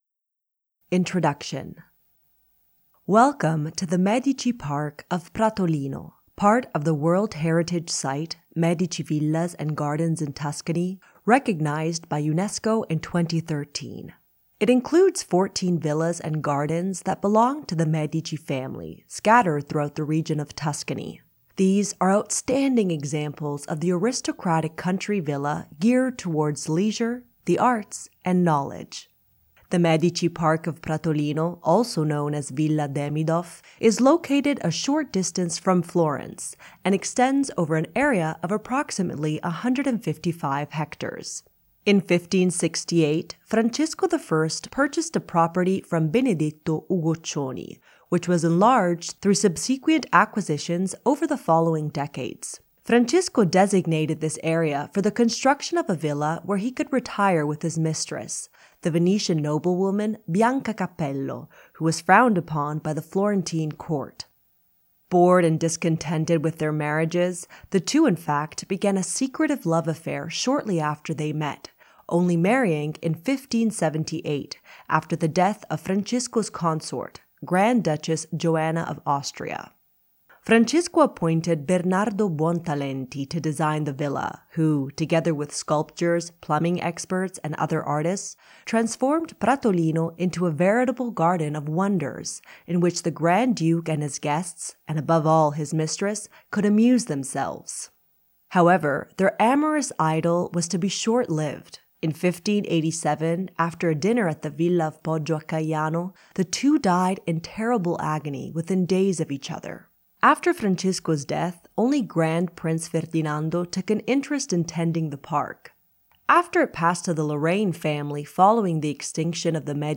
Audioguide of the Medici Park of Pratolino